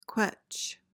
PRONUNCIATION: (kwech) MEANING: verb intr.: 1.